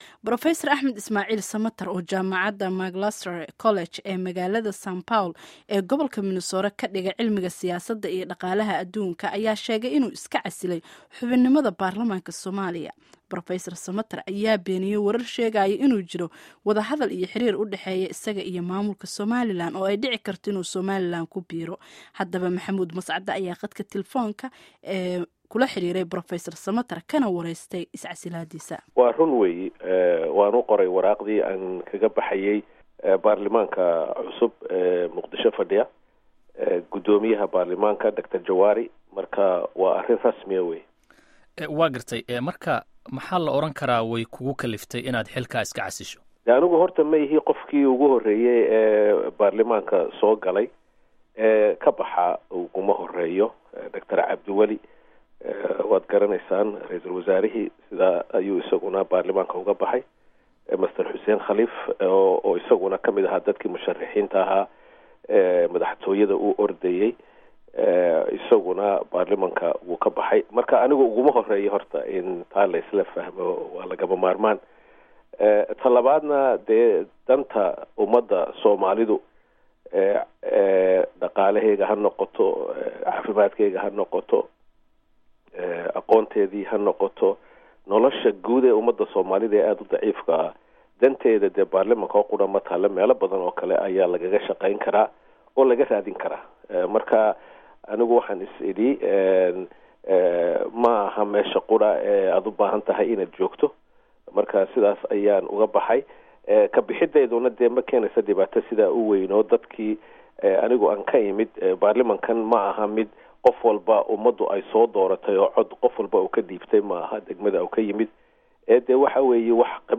Wareysiga Axmed Ismaciil